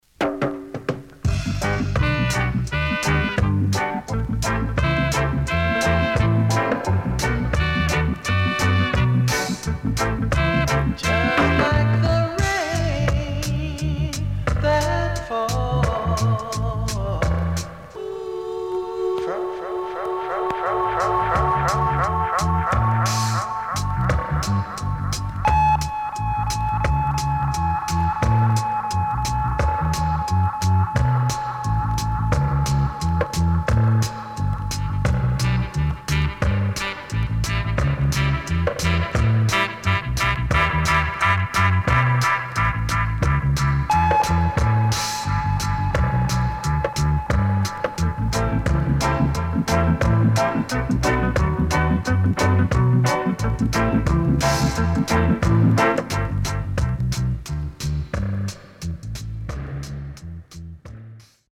SIDE A:薄いヒスノイズあります。